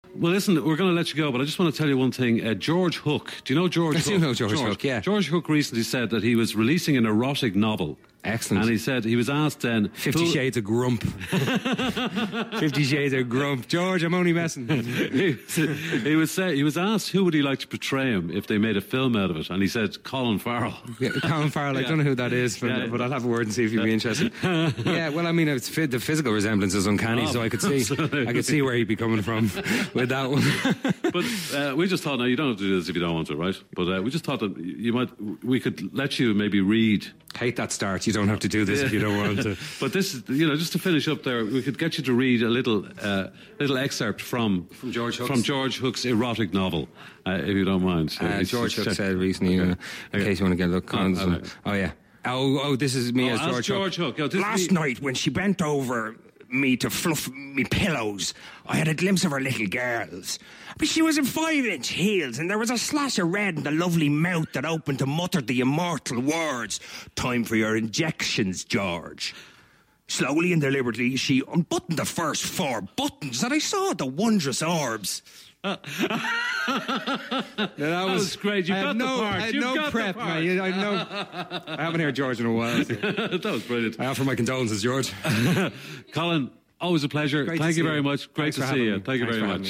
Today FM is on the road and is currently in Los Angeles so Colin Farrell popped in to the Ian Dempsey Breakfast Show this morning to chat about his new movie 'The Lobster', the success of True Detective, doing yoga but most importantly, George Hook's erotic novel.
Colin then went on to do an impromptu audition for the role, reading out an excerpt from the novel and for someone who had no time to prepare he did a bloody good job, nailing the unique George accent.
Colin-Farrell-voicing-George-Hook.mp3